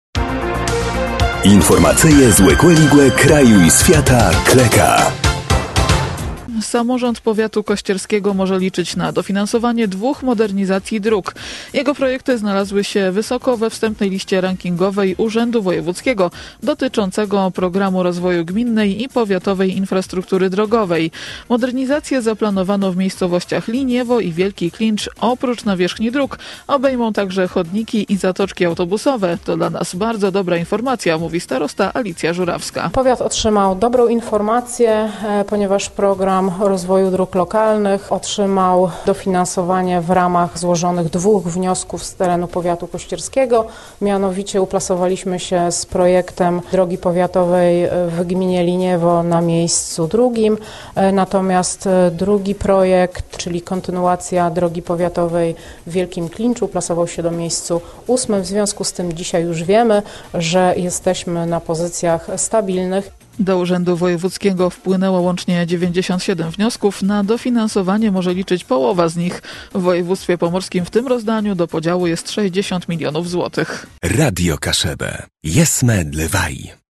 – To dla nas bardzo dobra informacja – mówi starosta Alicja Żurawska.